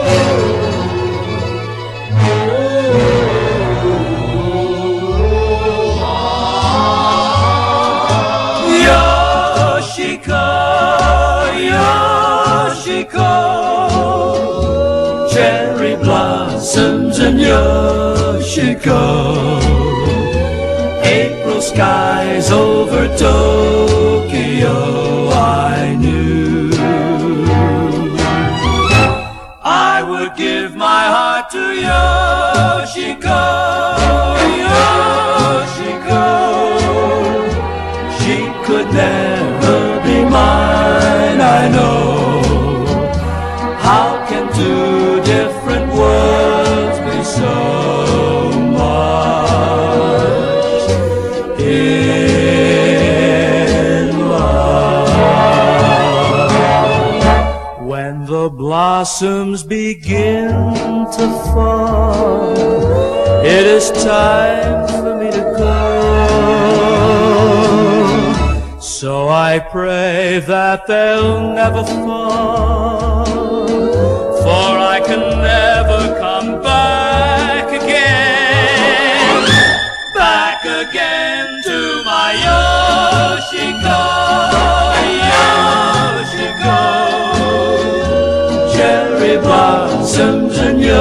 フィンランド産の現行メロディック・ポップ・パンク7インチ！